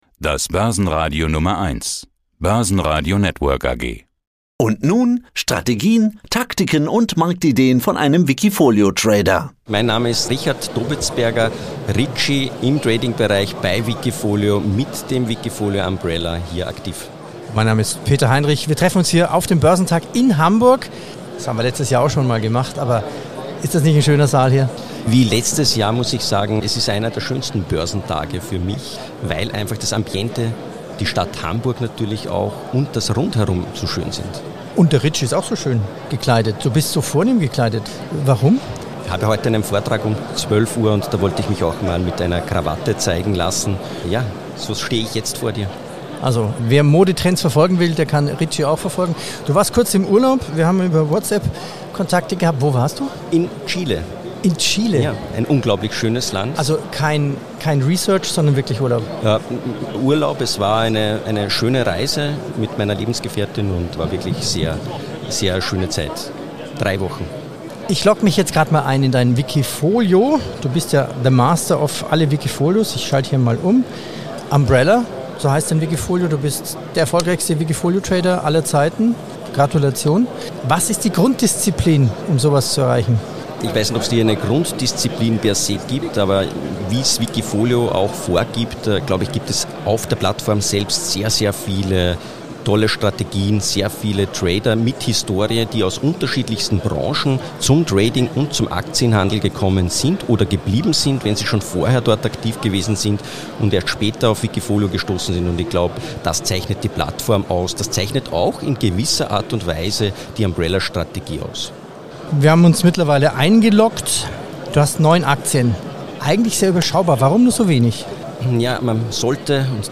Beschreibung vor 1 Jahr When the sun shines, we'll shine together … rundherum alles schön auf dem Hamburger Börsentag in der altehrwürdigen Wertpapierbörse der Hansestadt.